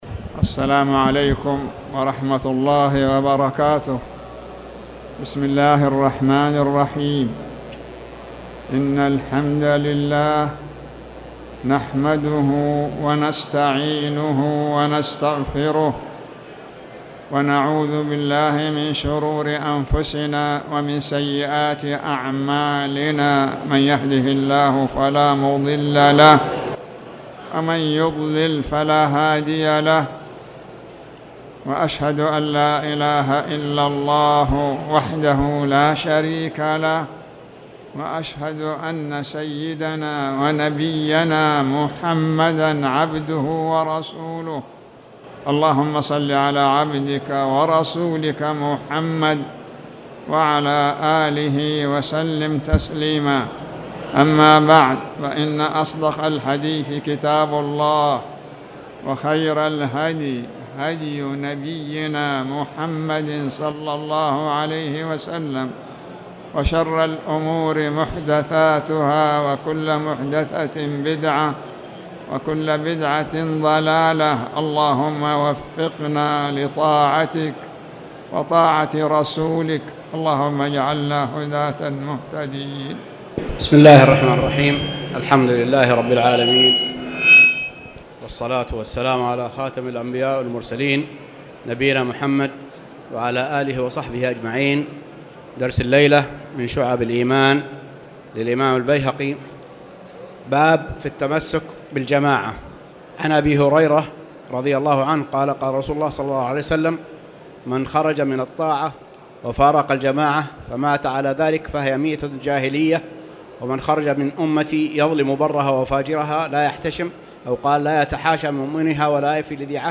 تاريخ النشر ٥ ربيع الأول ١٤٣٨ هـ المكان: المسجد الحرام الشيخ